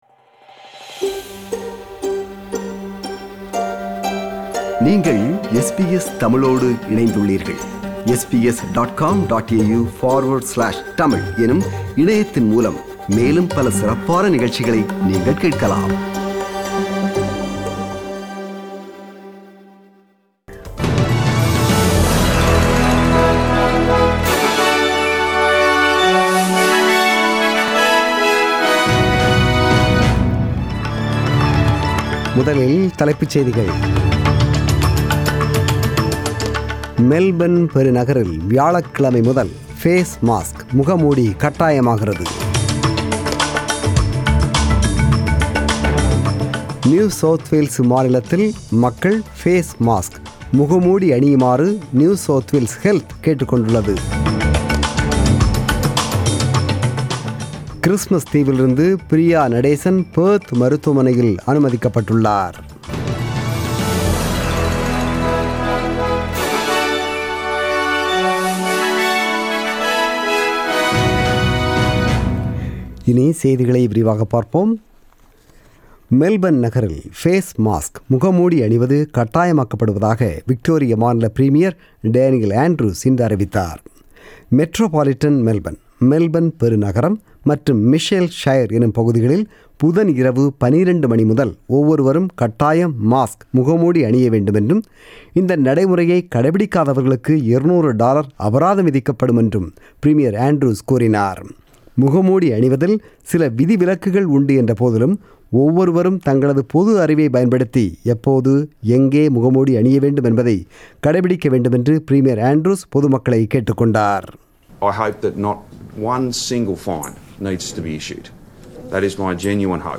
The news bulletin was broadcasted on 19 July 2020 (Sunday) at 8pm.